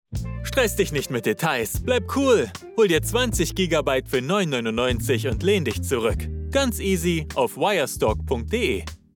Male
Approachable, Bright, Bubbly, Character
I record from my professionally treated home studio, delivering broadcast-ready audio.
Raw Sample.mp3
Microphone: Neumann TLM 103